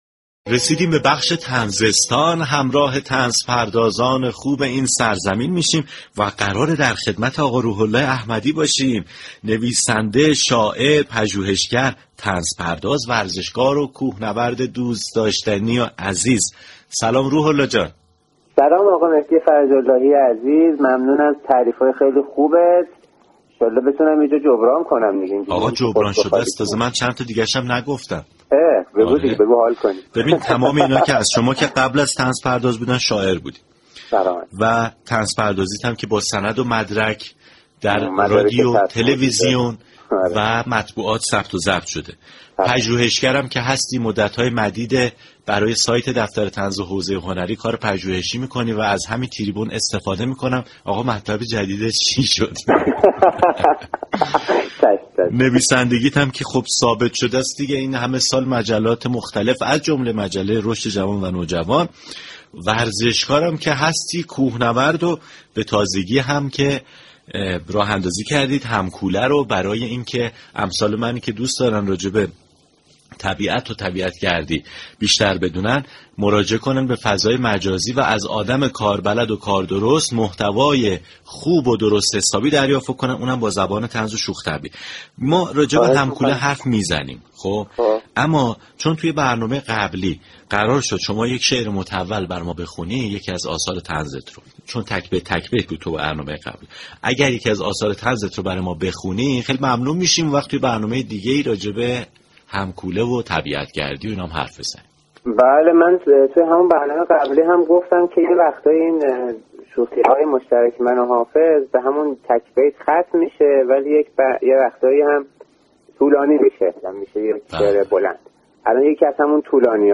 شنونده گفتگوی